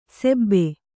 ♪ sebbe